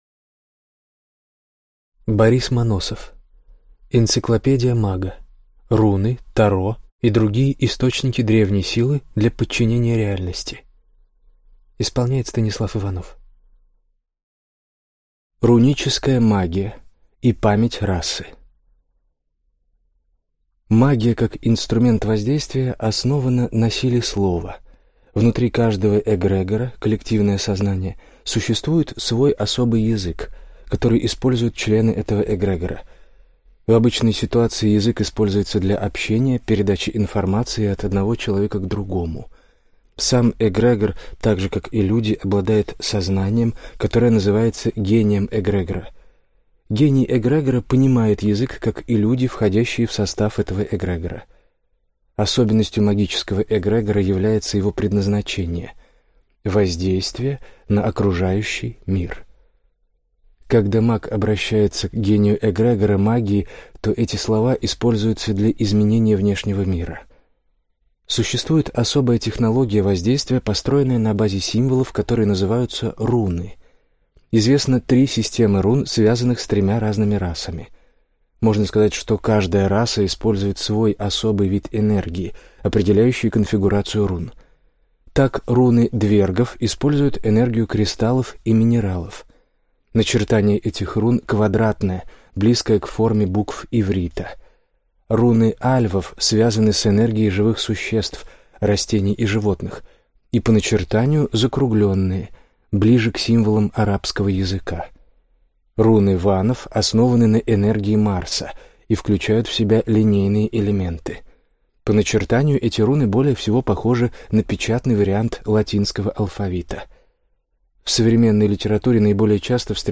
Аудиокнига Энциклопедия мага. Руны, Таро и другие источники древней силы для подчинения реальности | Библиотека аудиокниг